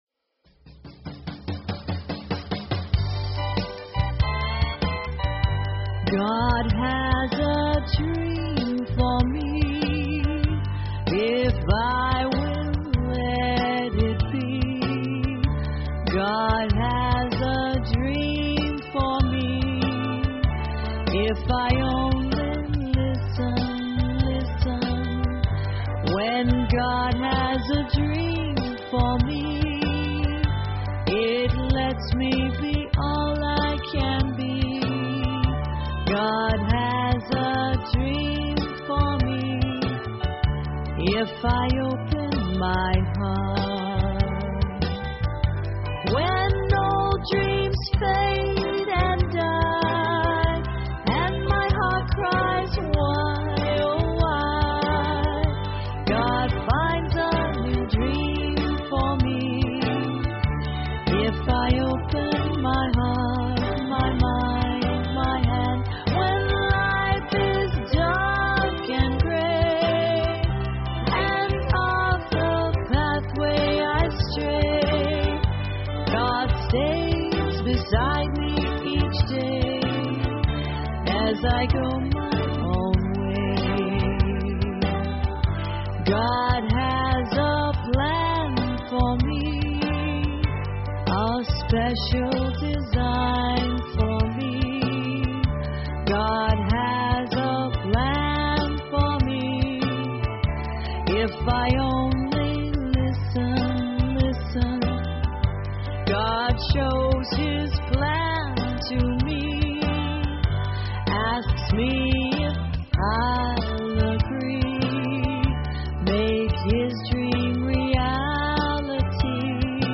Talk Show Episode, Audio Podcast, Inner_Garden_Online_Chapel and Courtesy of BBS Radio on , show guests , about , categorized as